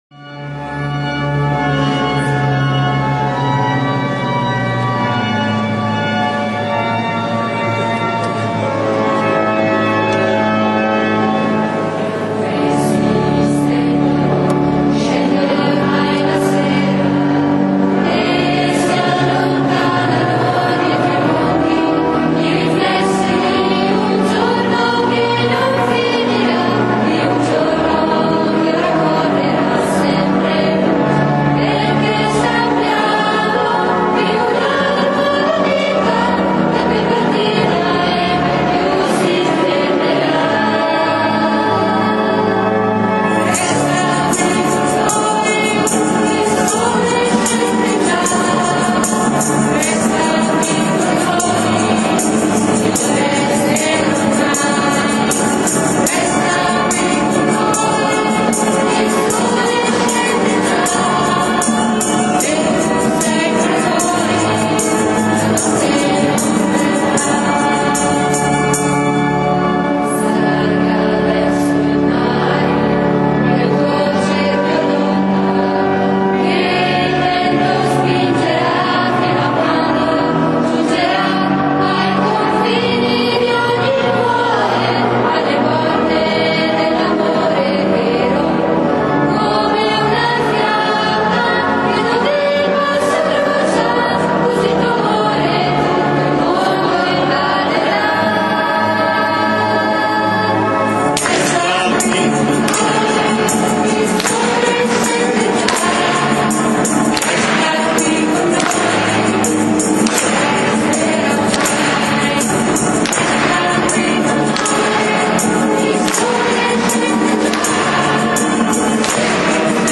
IV Dom. di Pasqua (Il Buon Pastore) - (Le Cresime)
canto: